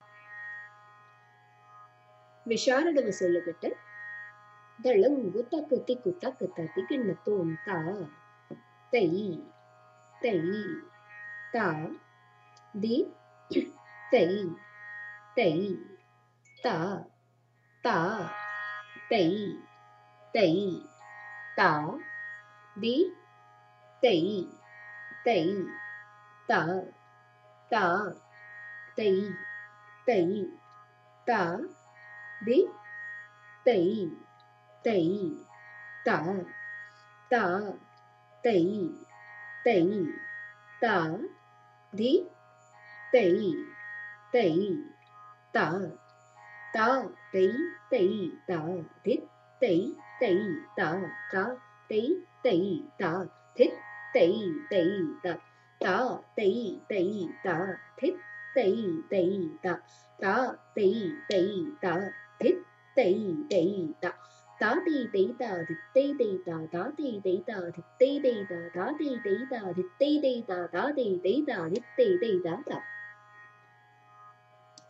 This Audio gives the sollukettu ie Bols or syllables for Visharu Adavu. It is sung in three speeds for the purpose of practice.